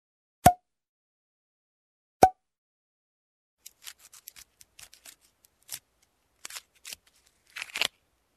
Пробка